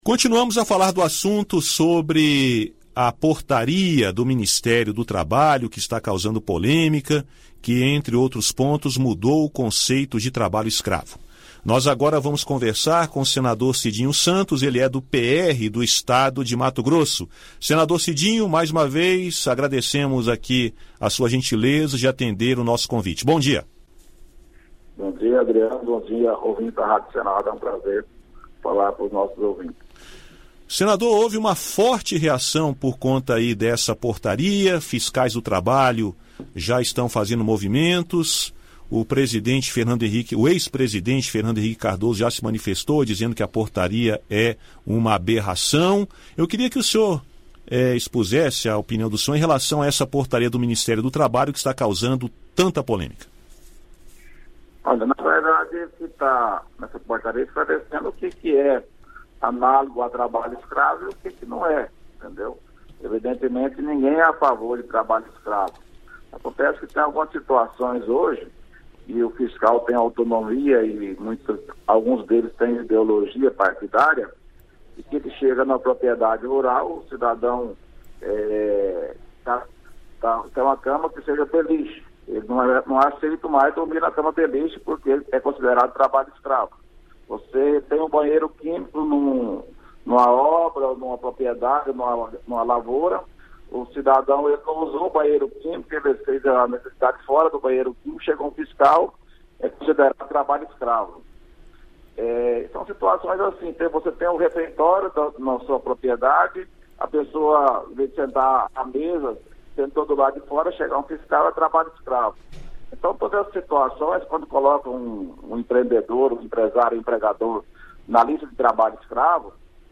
O senador Cidinho Santos (PR-MT) defendeu, em entrevista à Rádio Senado, a portaria do Ministério do Trabalho que alterou a fiscalização contra o trabalho escravo.